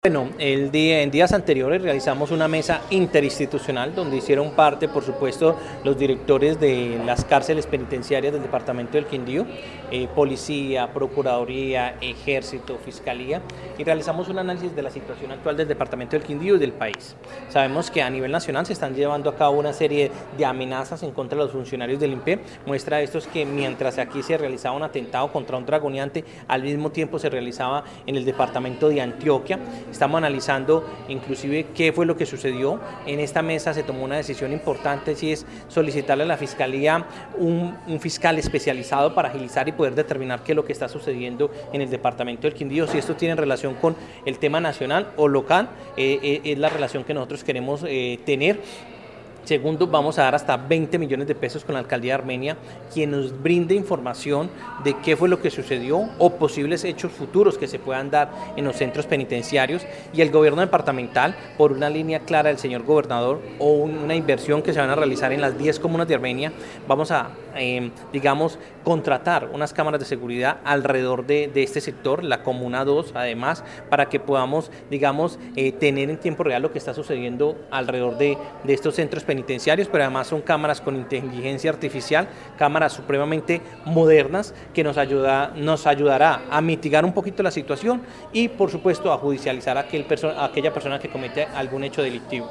Secretario del Interior del Quindío